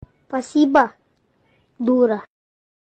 spasibo dura Meme Sound Effect